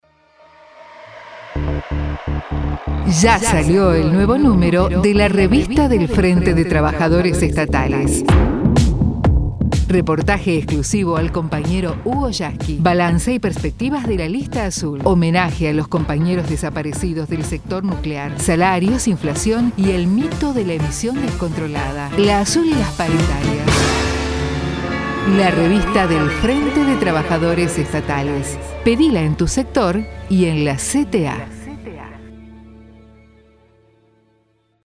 PROMO RADIAL de la revista del FRENTE DE TRABAJADORES ESTATALES